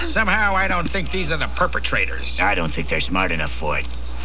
Cops talking about Kaneda and the gang
cops.wav